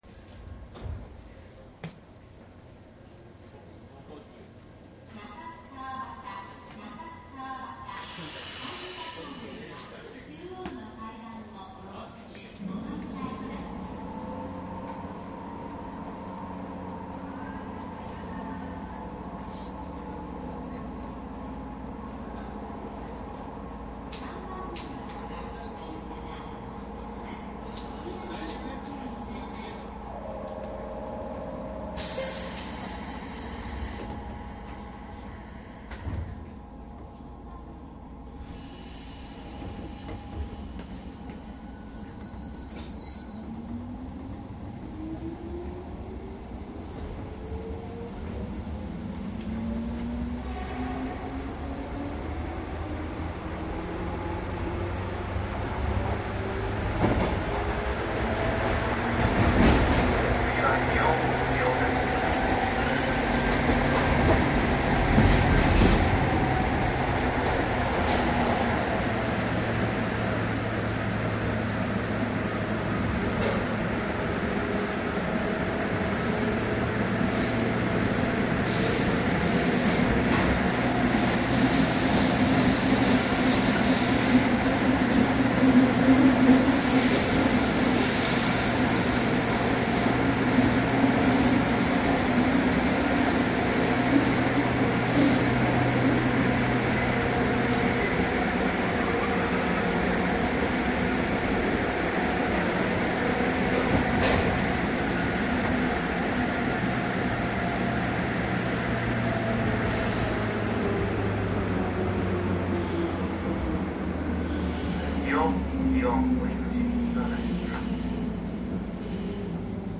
九州 筑肥線 103系鉄道走行音 ＣＤ♪
一部の駅が開業する前の録音です。
マスター音源はデジタル44.1kHz16ビット（マイクＥＣＭ959）で、これを編集ソフトでＣＤに焼いたものです。